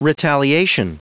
Prononciation du mot retaliation en anglais (fichier audio)
Prononciation du mot : retaliation